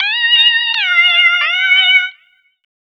02_Halloween_170_Bb.wav